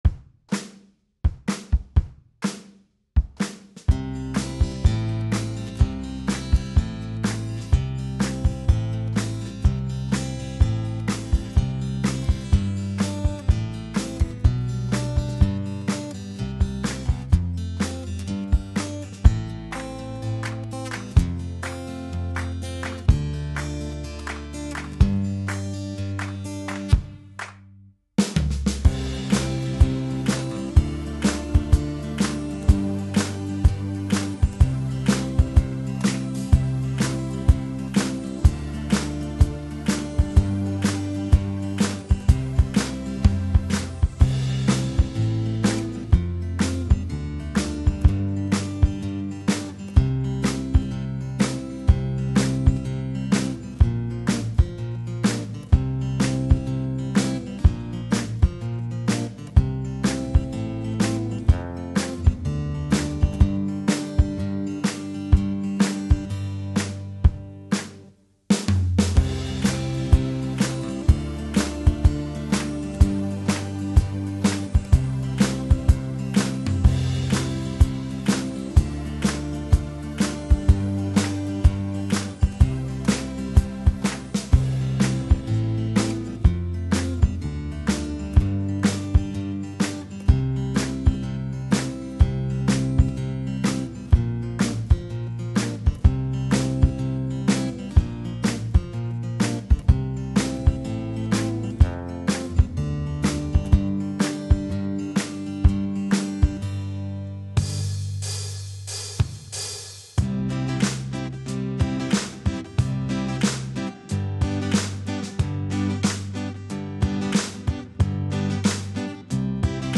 Unser Schullied
Lied - Wir sind die Weinbergskinder - ohne Gesang
Audio_ohne_Gesang.mp4